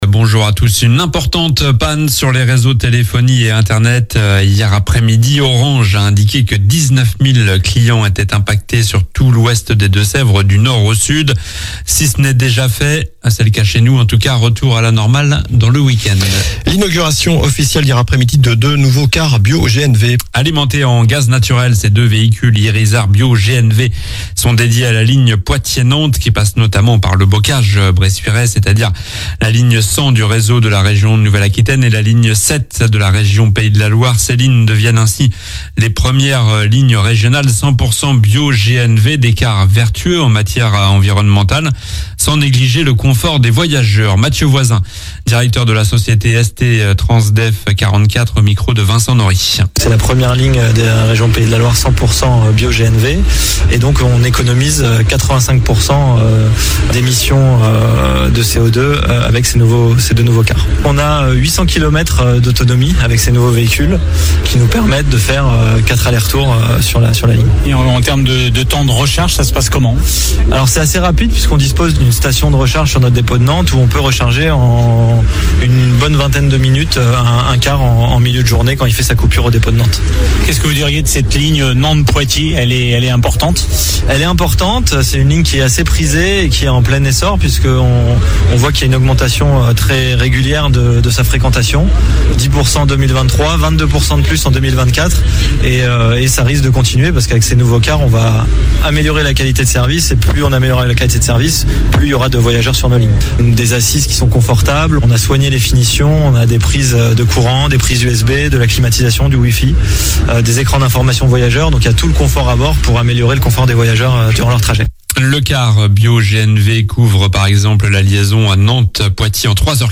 Journal du samedi 21 septembre (matin)